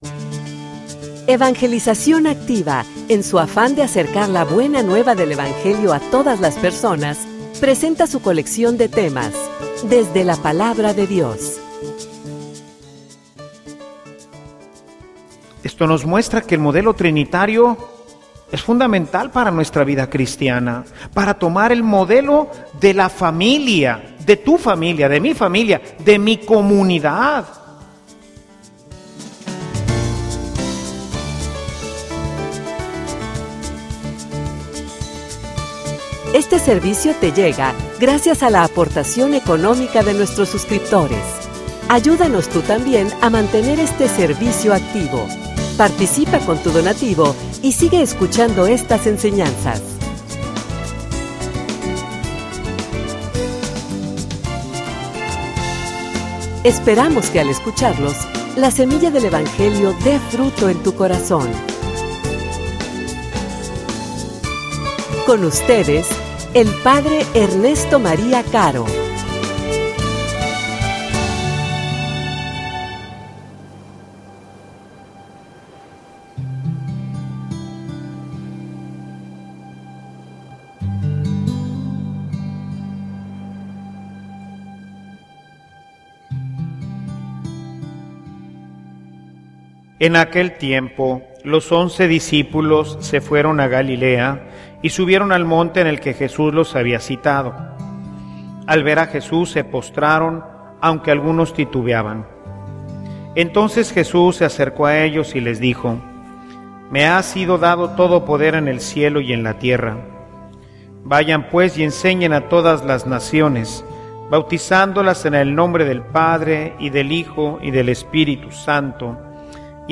homilia_Dios_es_familia.mp3